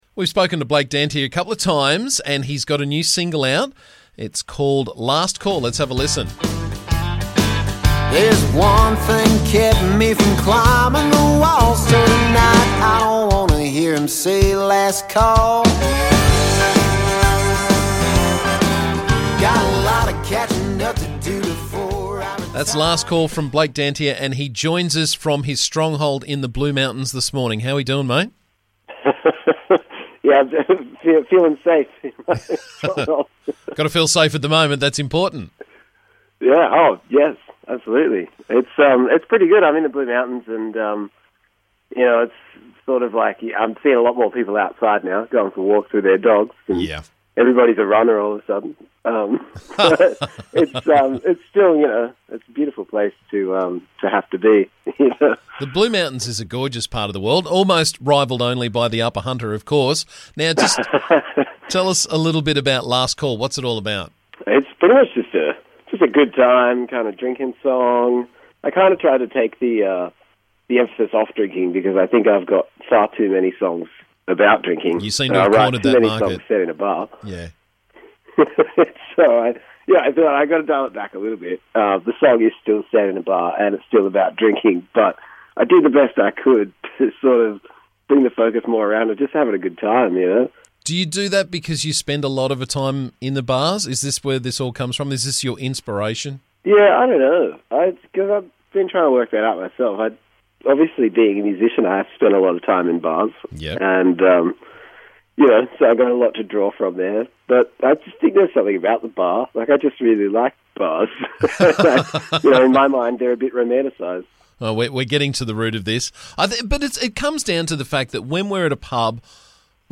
We caught up to talk about the song this morning and have a chat about why alcohol and bars are part of his music.